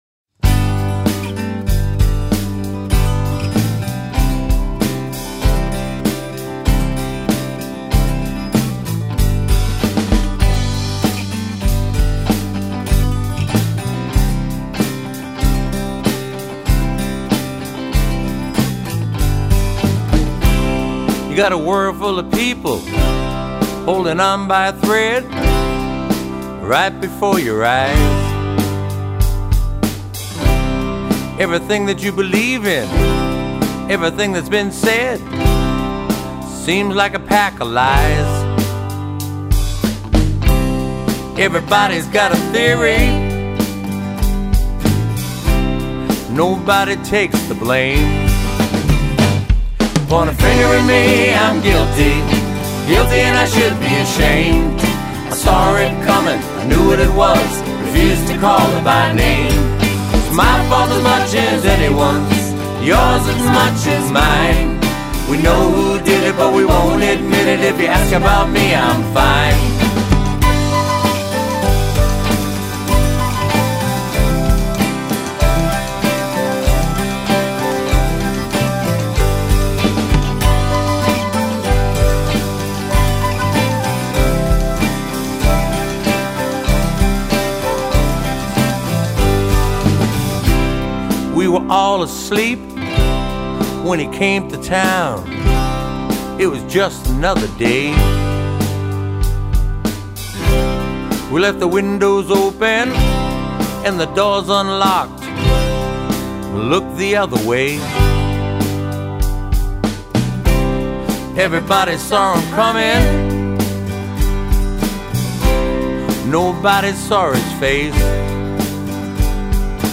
keys
drums, backup vocals
percussion
bass
sax, flute